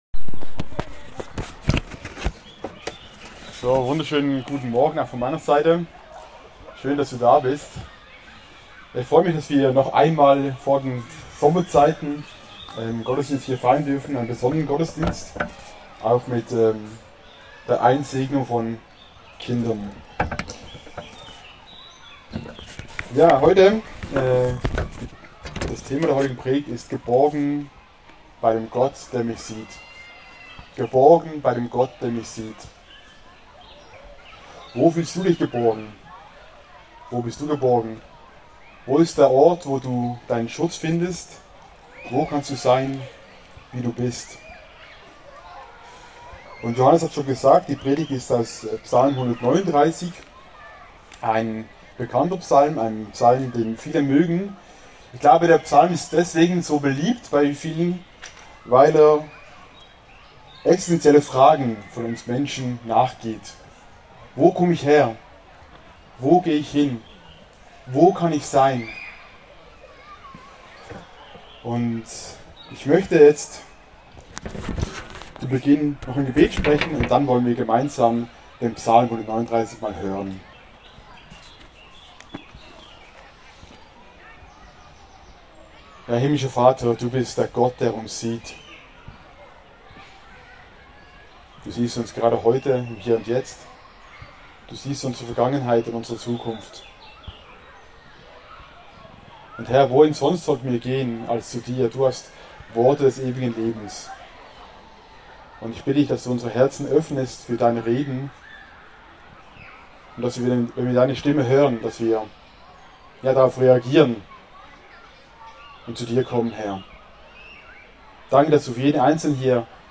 Predigt
der mich sieht Kindersegnungsgottesdienst im Strandbad Hemmingen